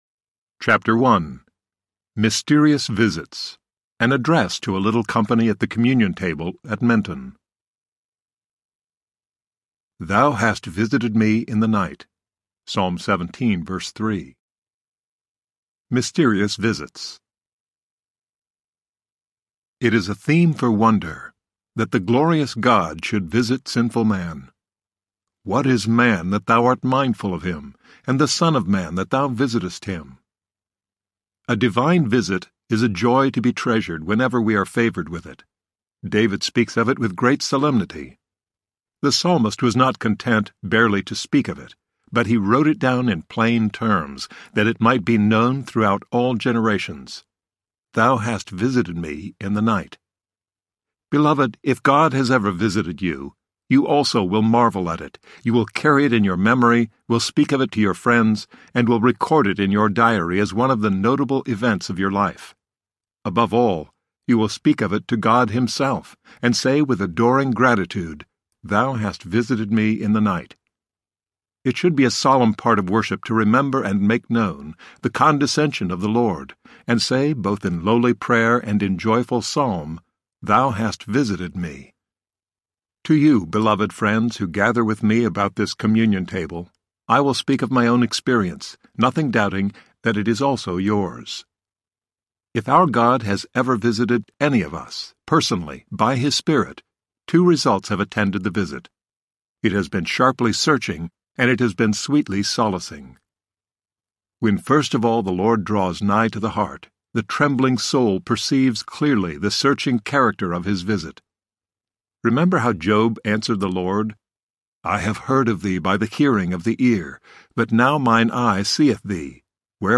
Until He Comes Audiobook
Narrator